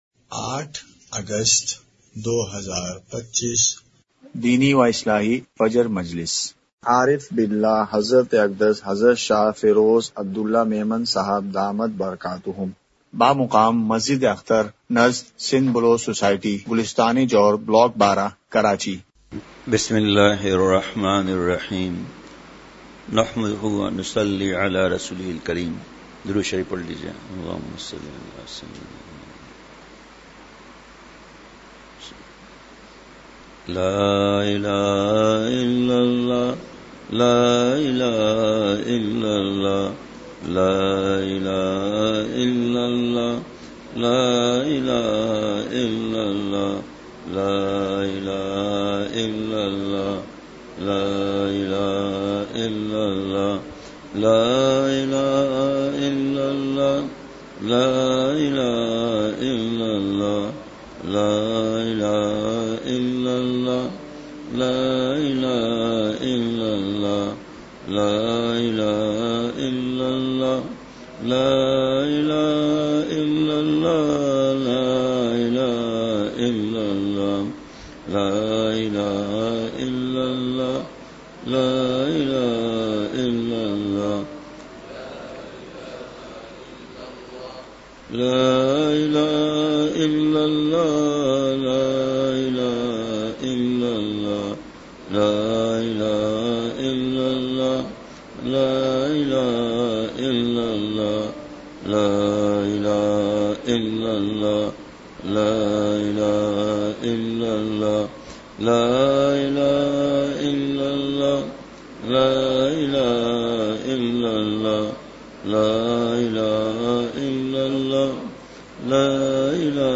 مقام:مسجد اختر نزد سندھ بلوچ سوسائٹی گلستانِ جوہر کراچی
مجلسِ ذکر:کلمہ طیّبہ کی ایک تسبیح!!